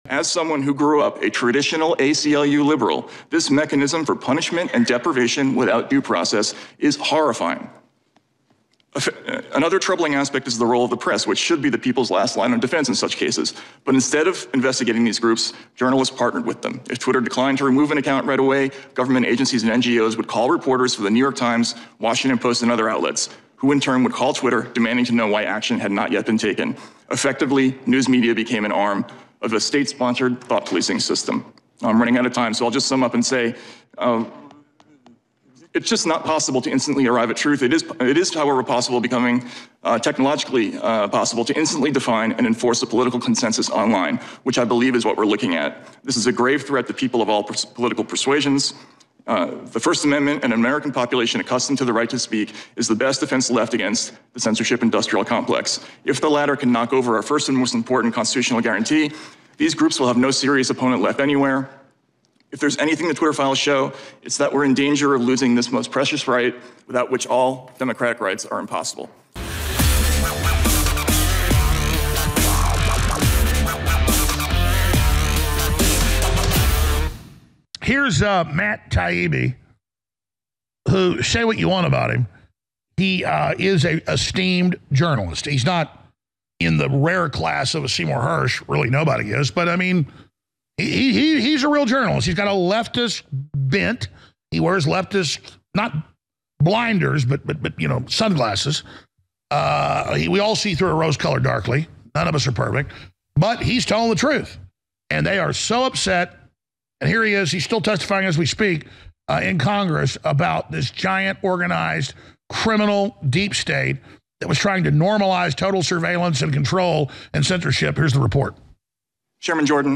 Watch Matt Taibbi's Bombshell Congressional Testimony Exposing Democrats' Secret Censorship Grid